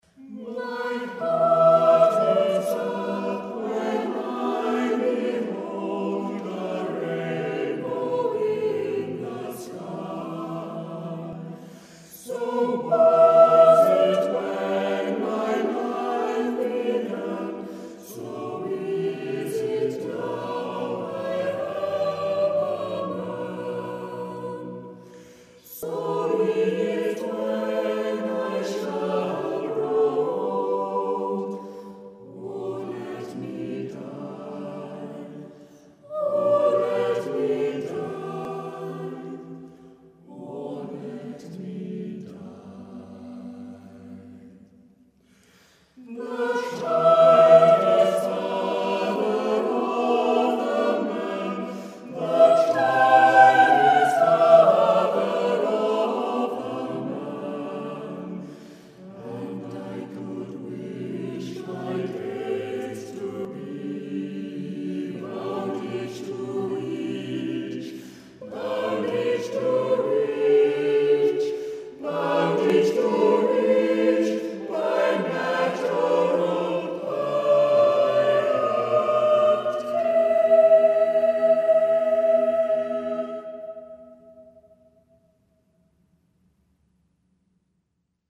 Unison